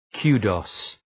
Προφορά
{‘ku:dəʋz}